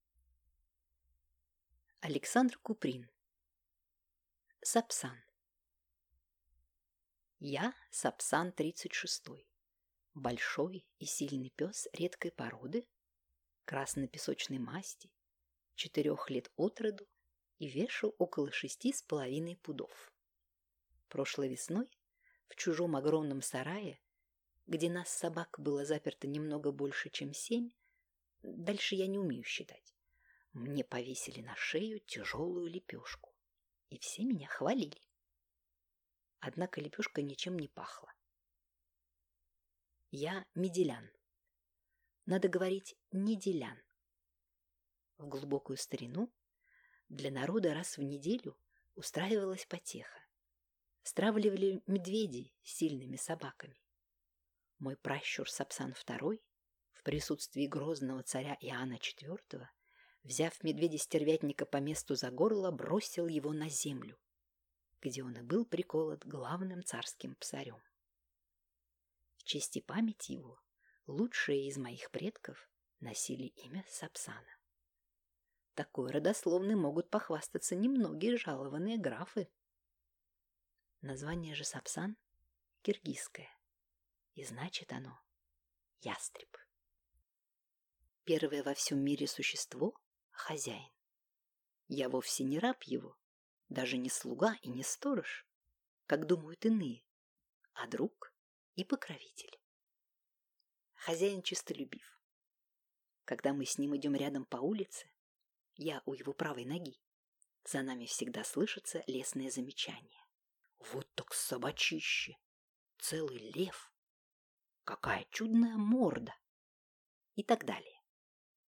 Аудиокнига Сапсан | Библиотека аудиокниг